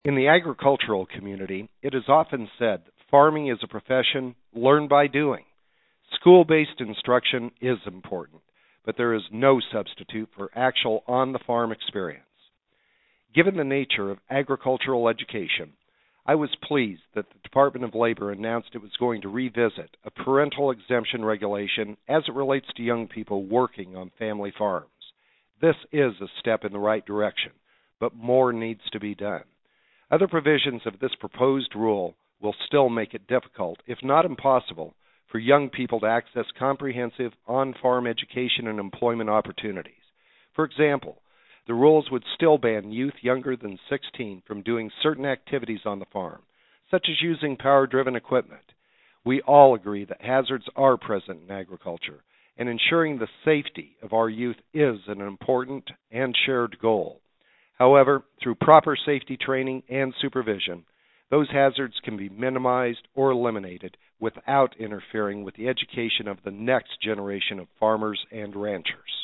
The Ag Minute, guest host Rep. Scott Tipton discusses the U.S. Department of Labor's (DOL) move to reconsider a portion of a proposed labor rule that relates to children working on family farms.  The decision to reconsider the rule is a positive development, but there are still concerns within the agricultural community.
The Ag Minute is Chairman Lucas's weekly radio address that is released from the House Agriculture Committee.